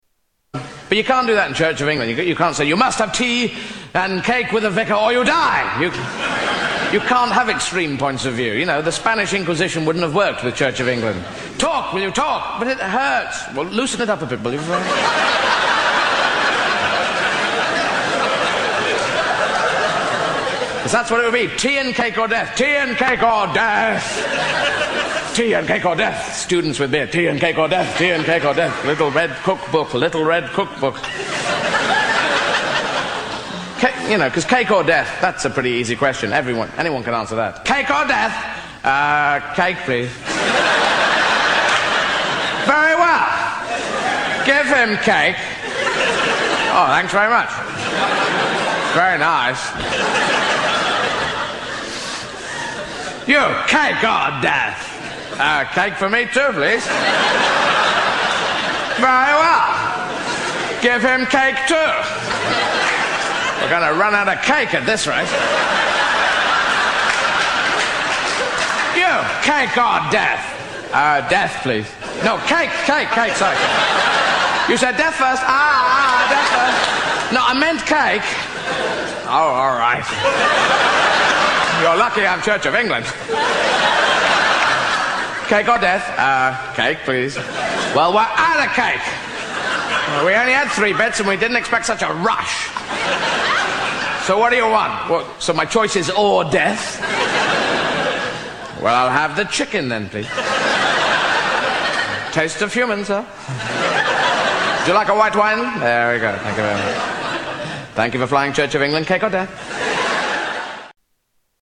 Tags: Comedians Eddie Izzard Eddie Izzard Soundboard Eddie Izzard Clips Stand-up Comedian